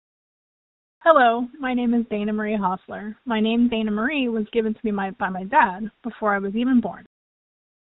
Audio Name Pronunciation